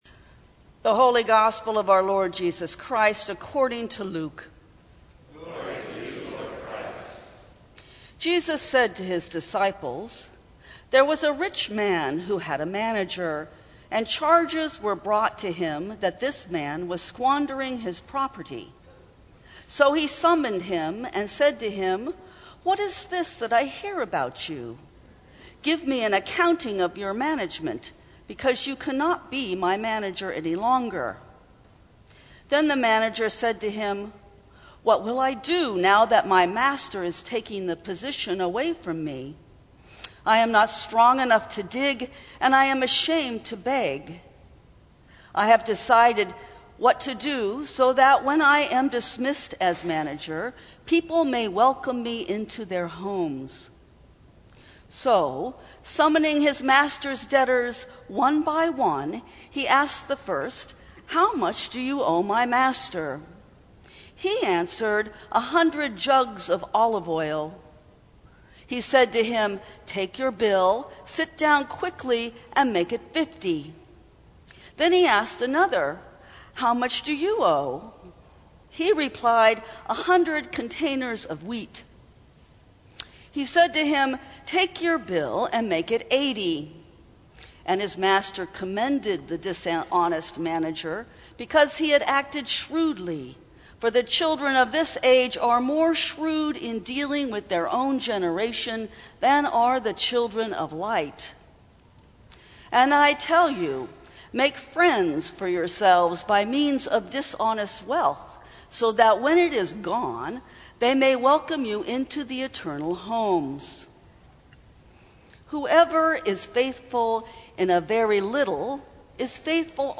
Sermons from St. Cross Episcopal Church Creative & Clever Sep 23 2019 | 00:12:51 1x 00:00 / 00:12:51 Subscribe Share Apple Podcasts Spotify Overcast RSS Feed Share Link Embed